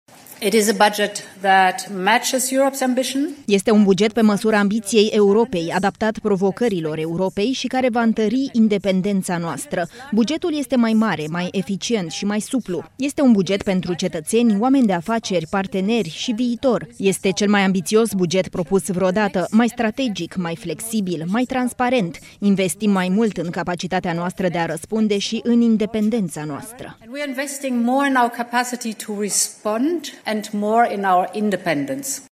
În paralel cu discuțiile din plenul Parlamentului European, președinta Comisiei, Ursula von der Leyen a susținut – într-o conferință de presă – că viitorul buget al Uniunii Europene – de două trilioane de euro – este cel mai ambițios buget de până acum al Uniunii.
Ursula von der Leyen, șefa Comisiei Europene: Este un buget adaptat provocărilor Europei